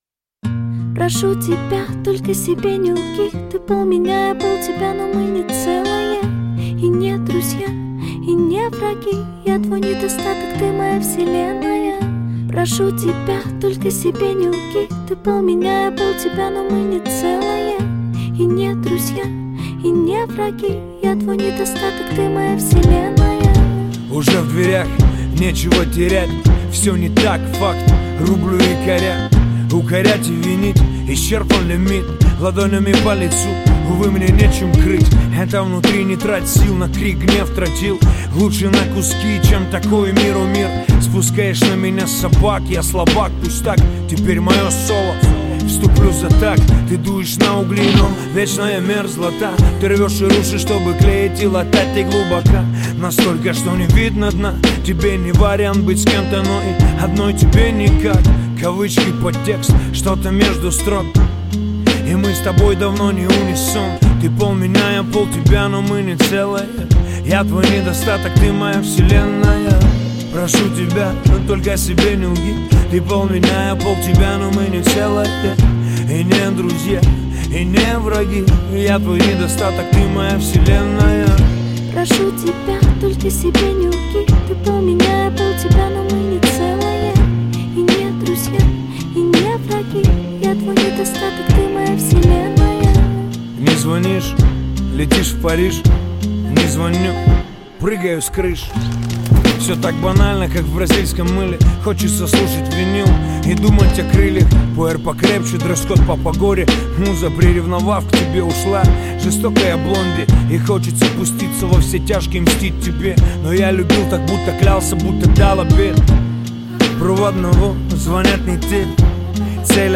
Жанр: Русский рэп / Хип-хоп